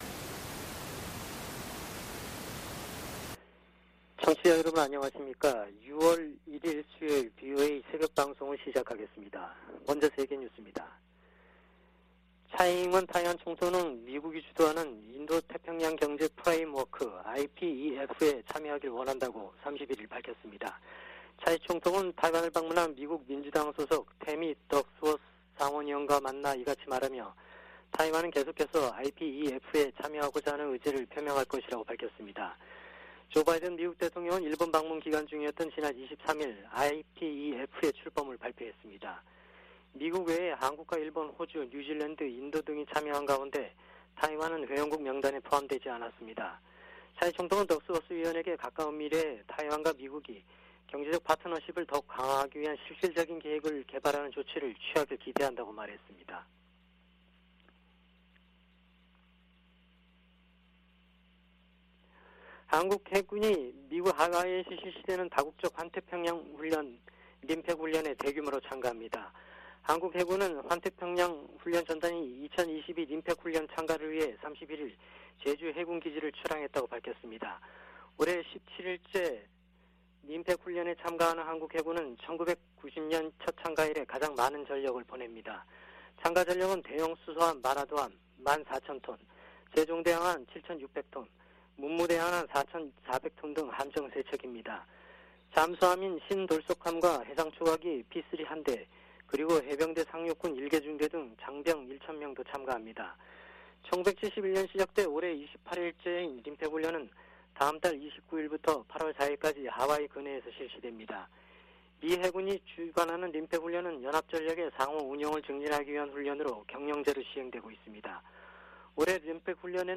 VOA 한국어 '출발 뉴스 쇼', 2022년 6월 1일 방송입니다. 조 바이든 미국 대통령이 메모리얼데이를 맞아 미군 참전 용사들의 희생을 기리고, 자유민주주의의 소중함을 강조했습니다.